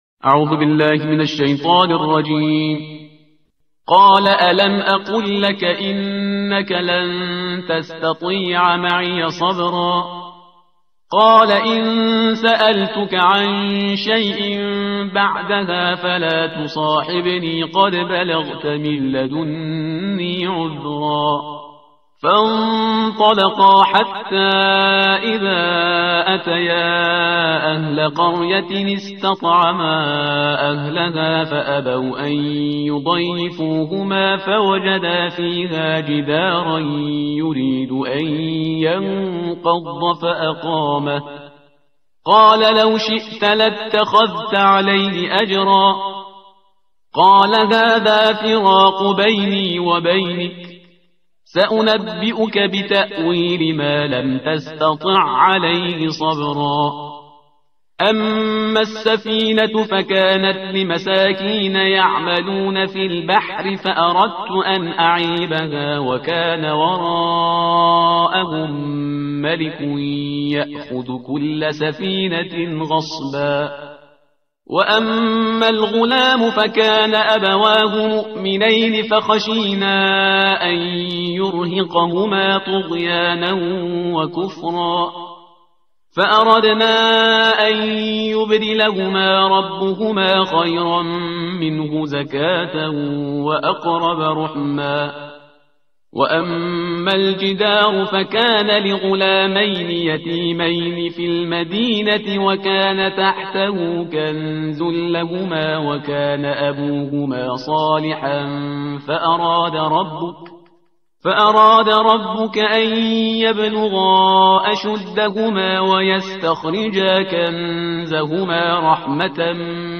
ترتیل صفحه 302 قرآن با صدای شهریار پرهیزگار